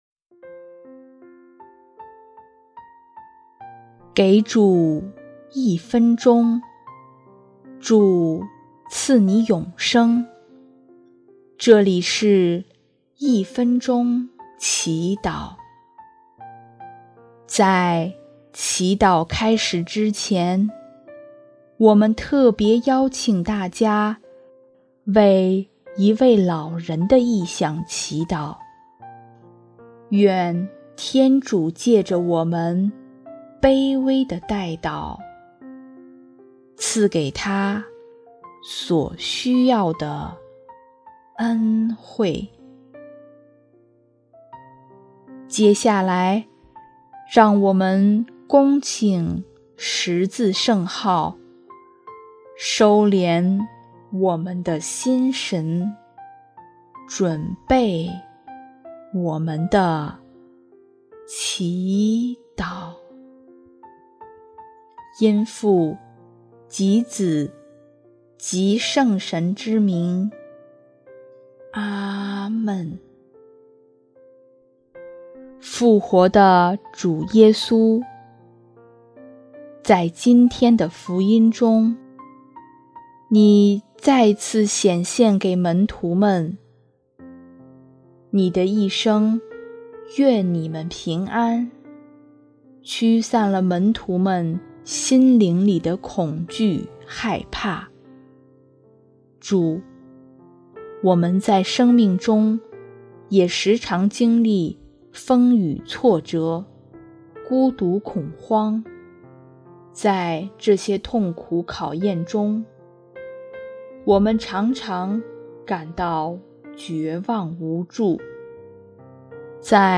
【一分钟祈祷】| 4月13日 祂的声音带来平安
音乐： 第三届华语圣歌大赛参赛歌曲《你是我的一切》（一位老人的悔改）